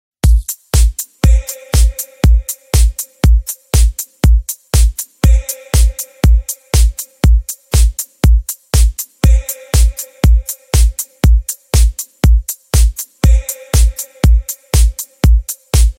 Dance Ringtones